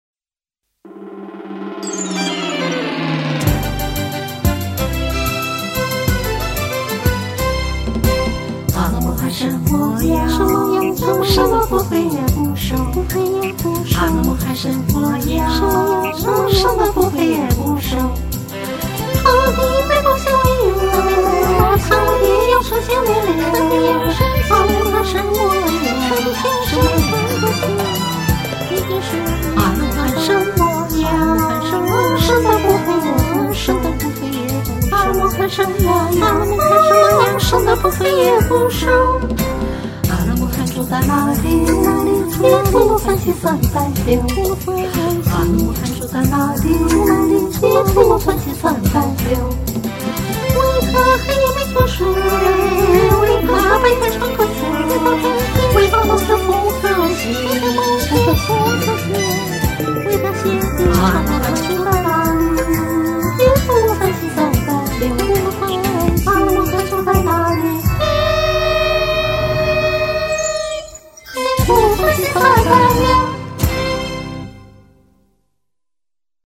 网上有免费做消音伴奏的软件，我就把这音频输了进去，然后就得到了这么一个没有主旋律的消音伴奏，叮叮咚咚的，我实在也听不出啥眉目，又在网上找了这首歌的二声部歌谱，就凑合着唱啦！